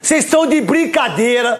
Apresentador esportivo Neto fica revoltado e fala "cês tão de brincadeira?"